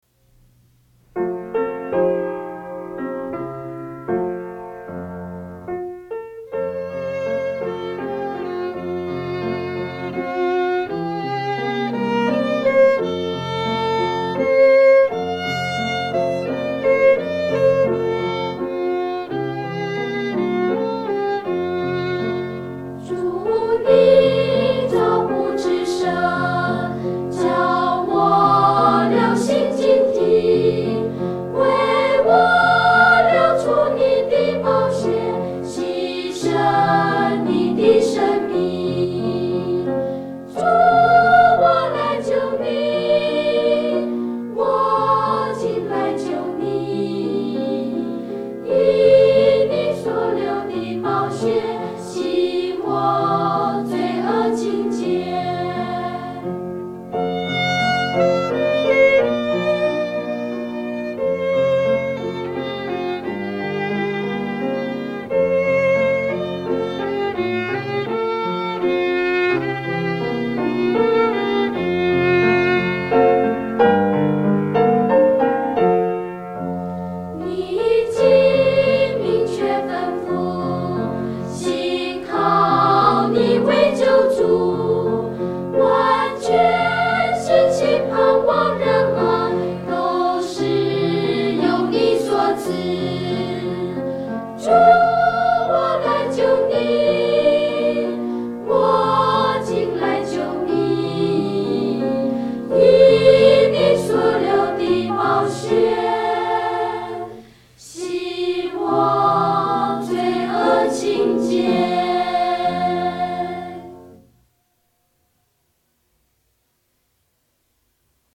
儿童赞美诗 | 我今来就你